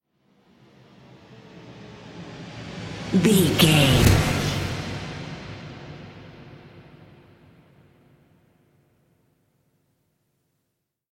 Atonal
synthesiser
percussion
ominous
dark
suspense
haunting
creepy